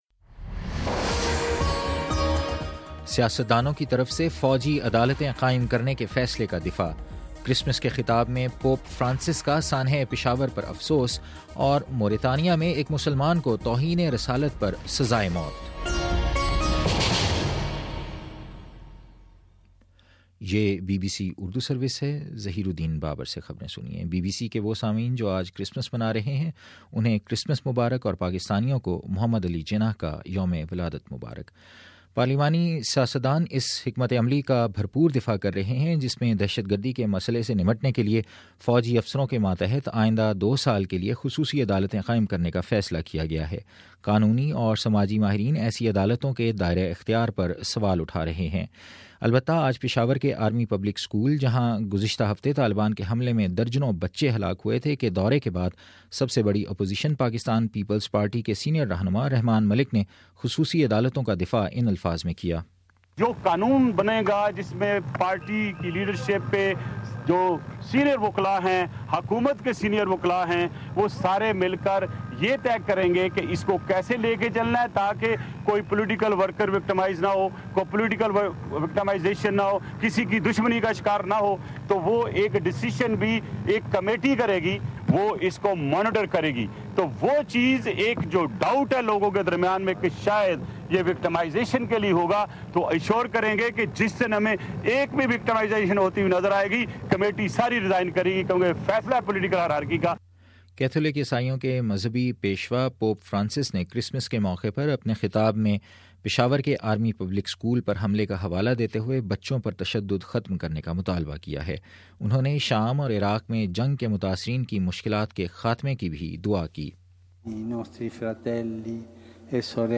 دسمبر 25 : شام چھ بجے کا نیوز بُلیٹن